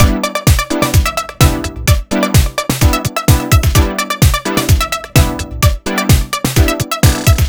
Retro Chic 128 Full.wav